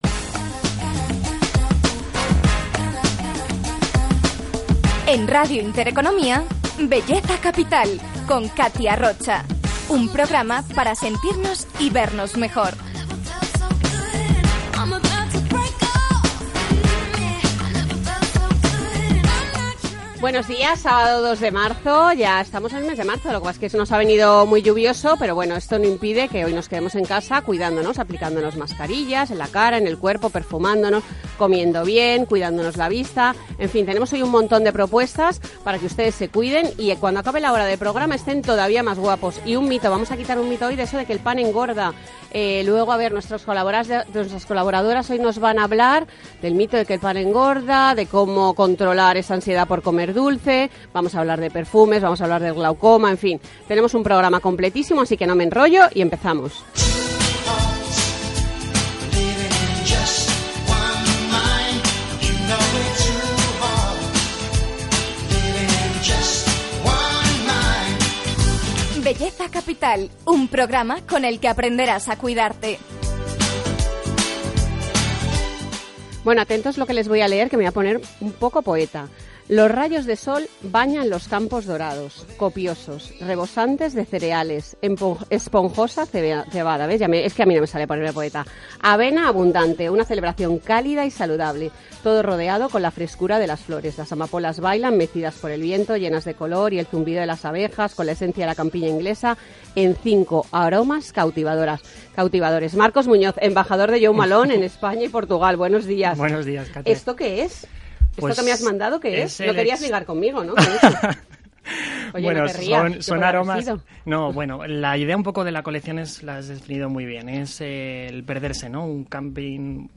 (desde el min. 17:32 hasta el min. 24:45) Escuchar entrevista El paradigma del glaucoma.